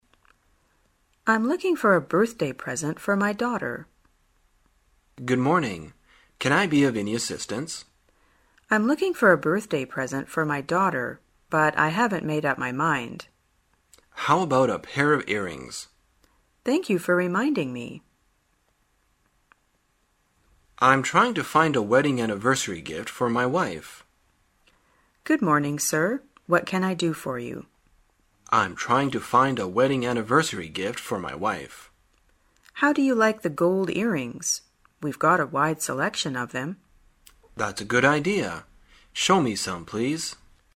旅游口语情景对话 第257天:如何说明所买礼品